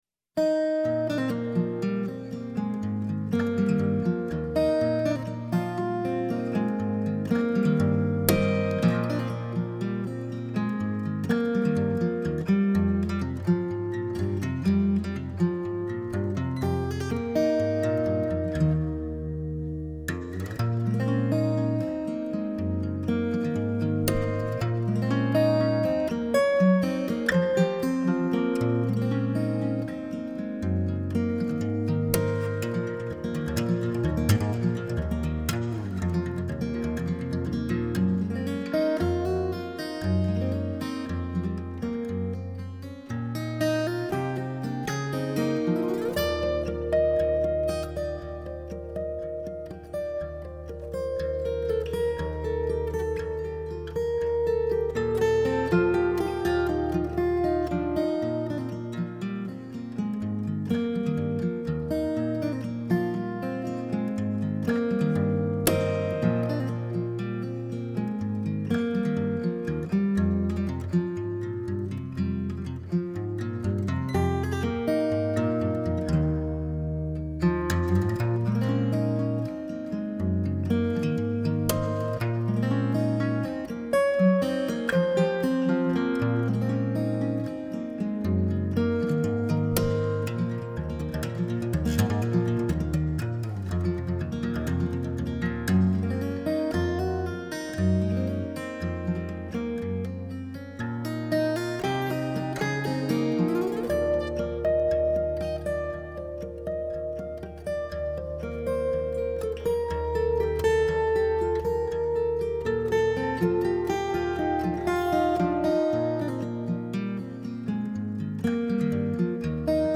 最纯净的现场双轨收音版本